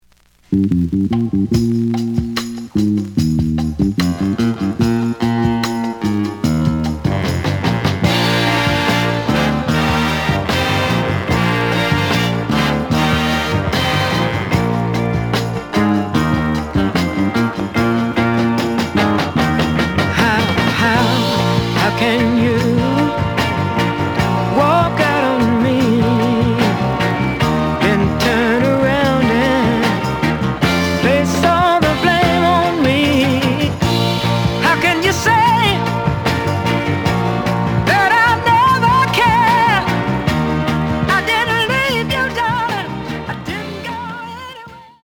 The audio sample is recorded from the actual item.
Slight noise on both sides.)